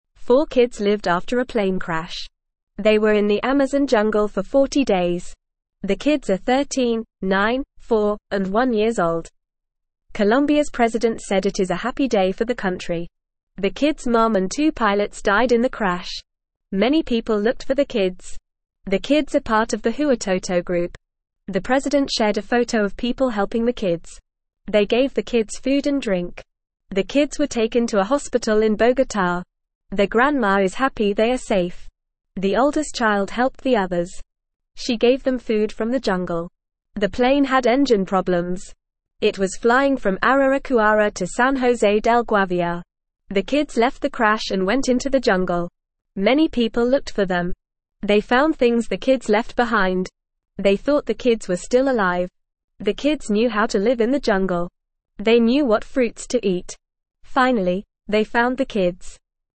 Fast
English-Newsroom-Beginner-FAST-Reading-Four-Kids-Survive-Jungle-Plane-Crash.mp3